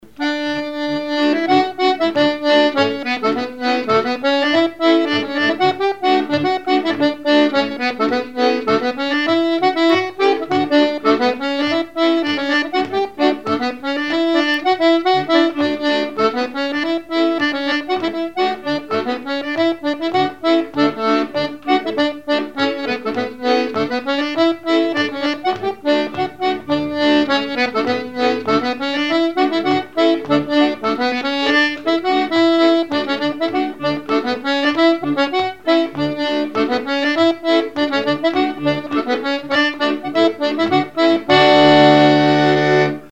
danse : mazurka
instrumentaux à l'accordéon diatonique
Pièce musicale inédite